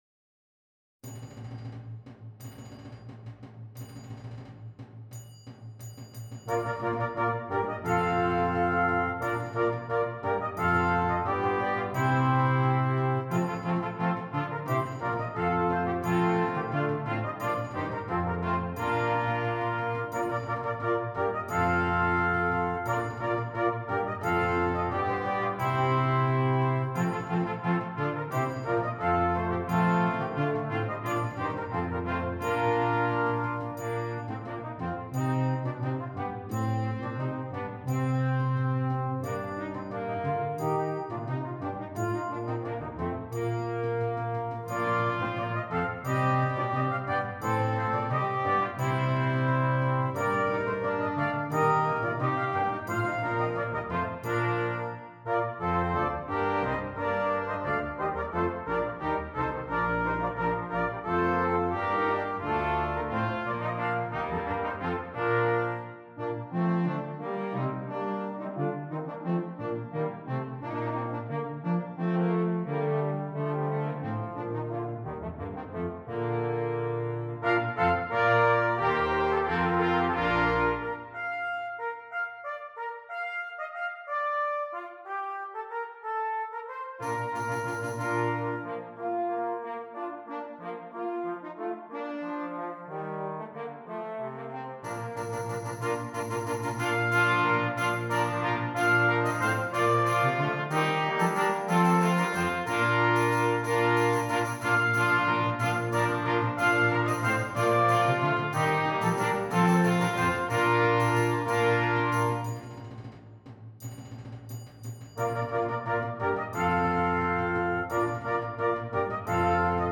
Brass Quintet - optional Percussion